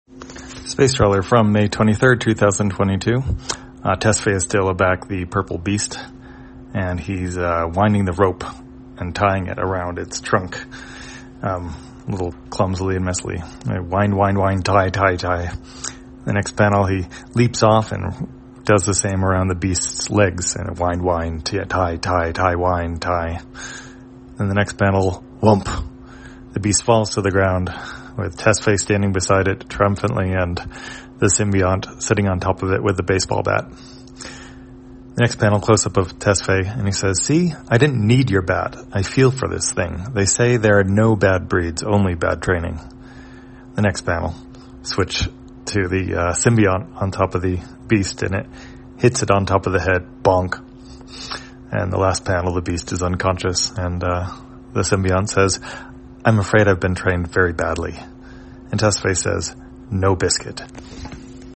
Spacetrawler, audio version For the blind or visually impaired, May 23, 2022.